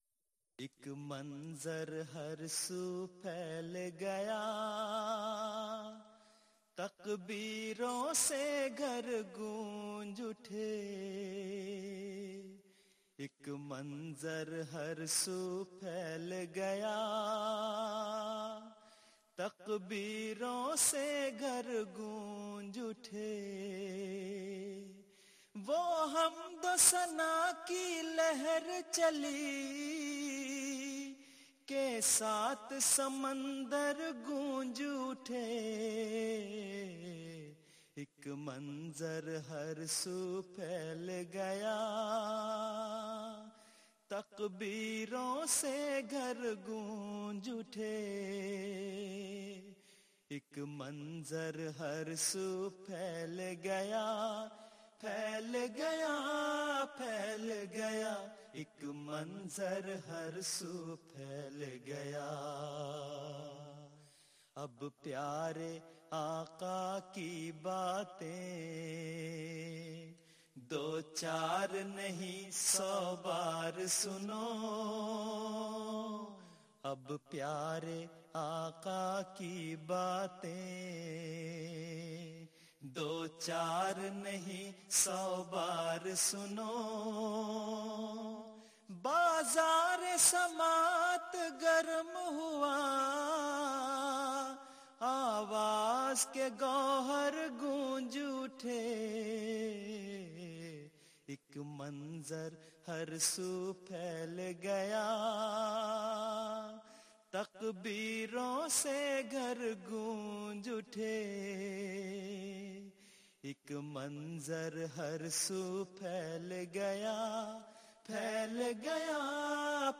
نظمیں (Urdu Poems)